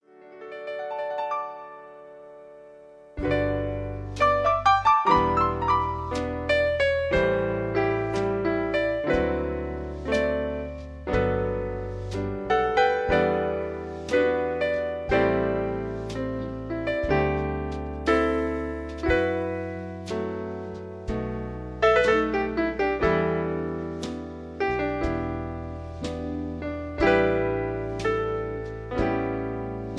Slow Piano Version-2) Karaoke MP3 Backing Tracks
backing tracks